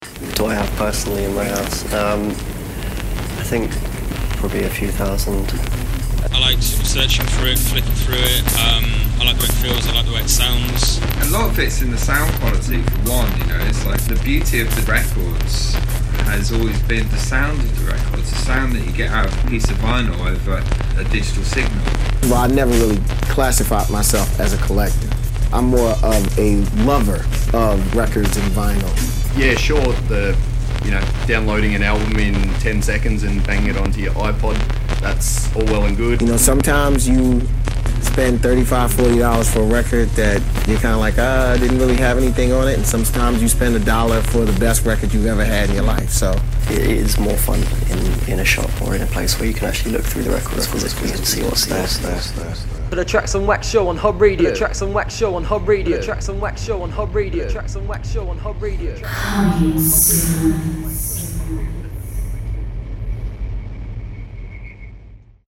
Advert for the upcoming Trax On Wax Show on Hub Radio